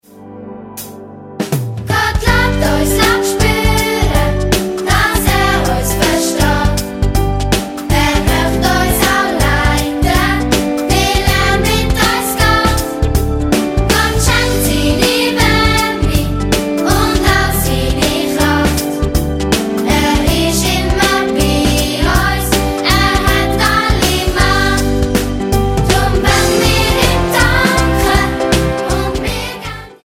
Mundart-Worshipsongs für Kids